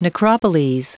Prononciation du mot necropoles en anglais (fichier audio)